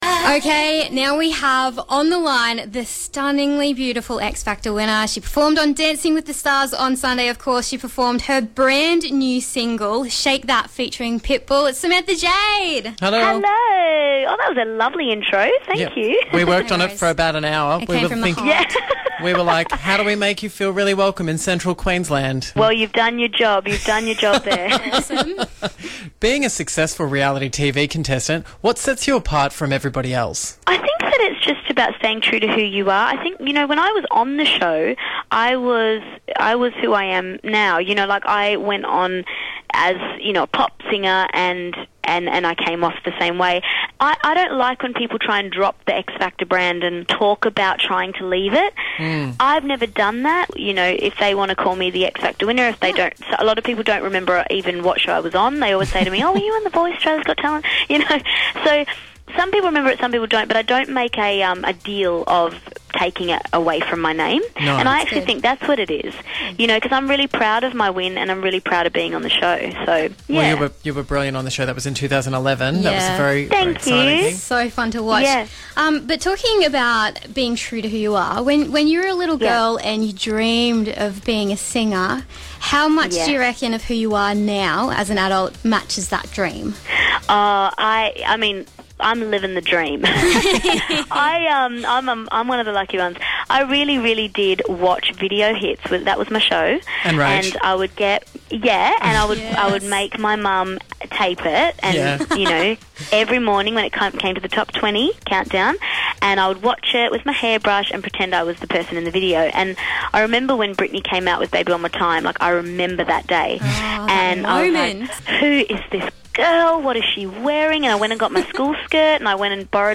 Samantha Jade Interview